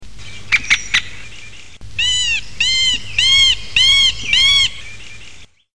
Dendrocopus medius
pic-mar.mp3